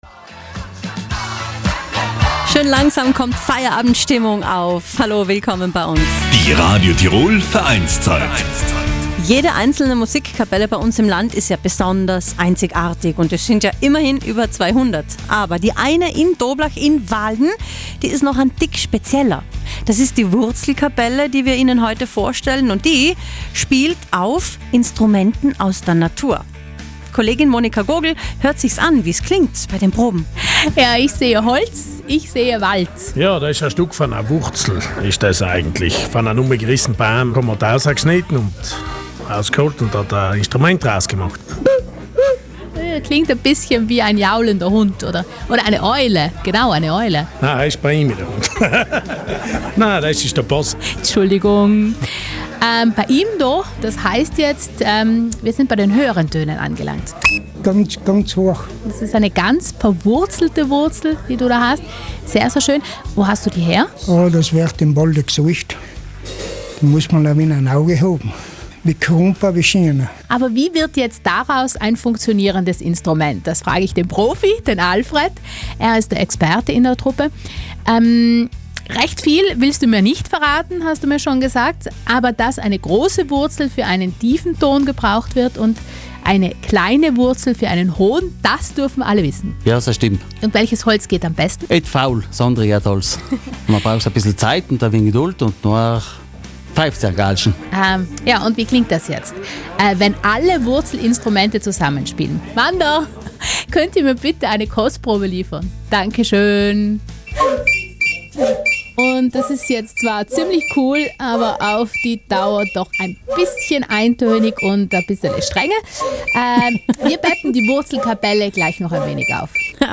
Die Instrumente kommen mitten aus dem Wald. Die Musikkapelle Wahlen sammelt Wurzeln, große, kleine, je krummer desto besser und verwendet sie als Blasinstrumente.
Natürlich gibt’s eine kleine Hörprobe.